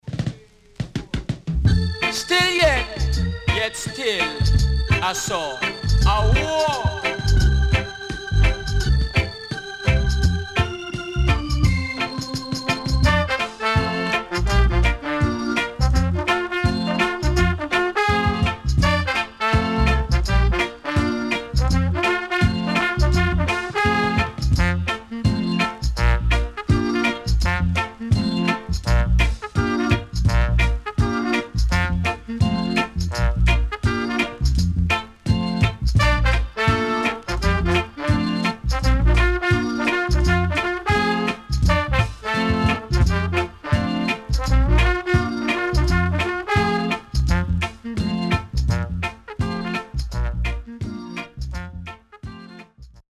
Funky Deejay
SIDE A:薄くヒスノイズ入りますが良好です。